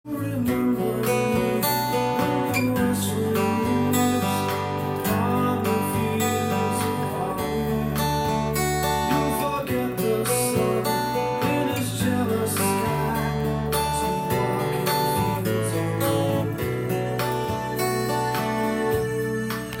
音源にあわせてアルペジオ弾いてみました
イントロはBm7のみで